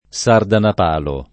DOP: Dizionario di Ortografia e Pronunzia della lingua italiana
Sardanap#lo] pers. m. stor. — dalla figuraz. leggendaria del re assiro Assurbanipal (m. 626 a. C.) l’uso antonomastico del nome S. (talvolta col suo pl. -li) per «riccone dissoluto e fastoso»: come i Sardanapali, ch’erano amici per ghiottornie e per guadagni [k1me i Sardanap#li, k $rano ami per gLottorn&e e pper gUad#n’n’i] (Mazzei); i canti Che il lombardo pungean Sardanapalo [i k#nti ke il lomb#rdo punJ%an Sardanap#lo] (Foscolo)